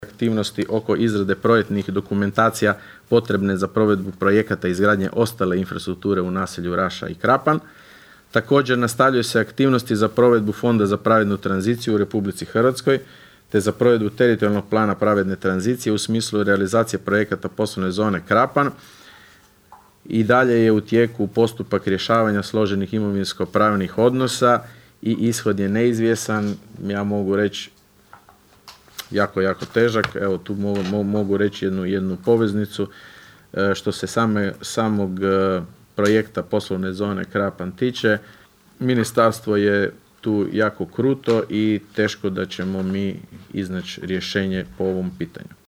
Na sinoćnjoj sjednici Općinskog vijeća Raše, općinski načelnik Leo Knapić podnio je izvješće o radu za prvih šest mjeseci 2025. godine.
ton – Leo Knapić 1).